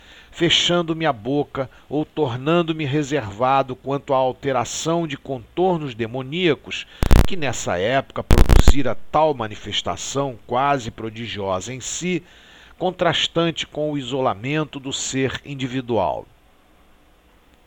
Recording with defects
Often, when recording, it happens something like a “short cut” in the middle of the words.
The words are not recorded and the program reproduces this strange sound for a few seconds, over the original sound.